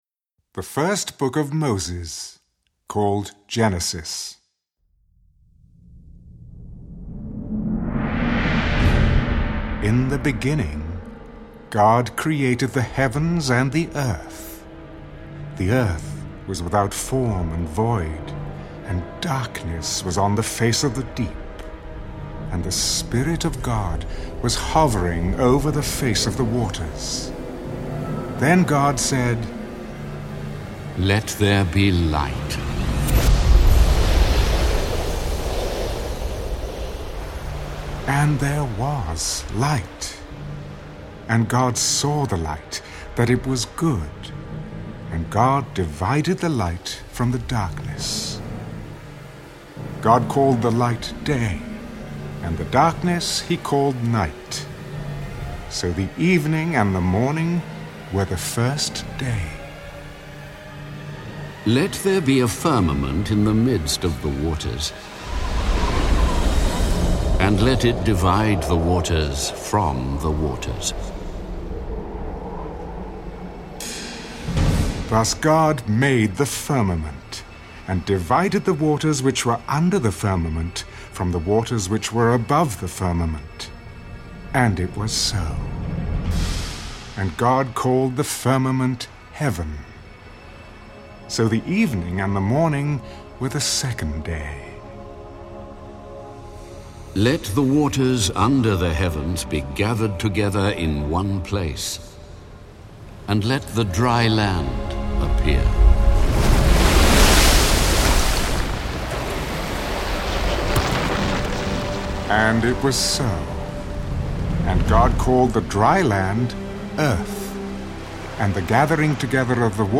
Best Dramatized Audio Bible player, the Word of Promise dramatized Bible